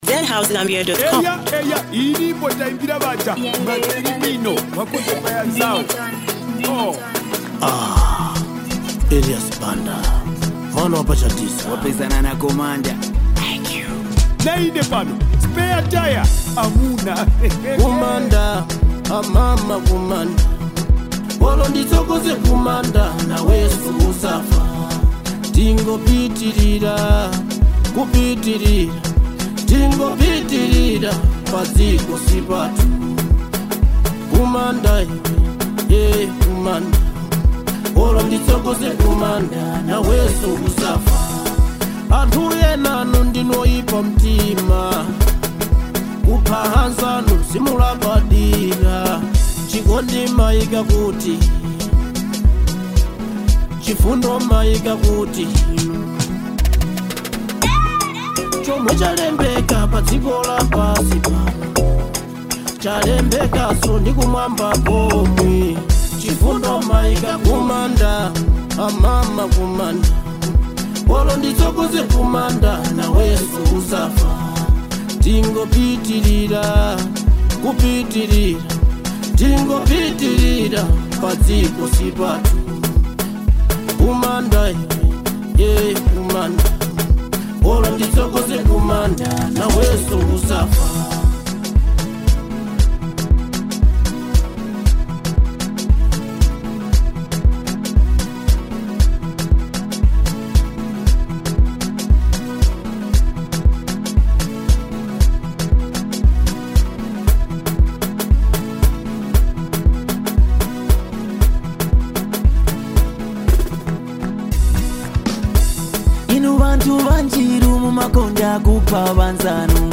powerful conscious track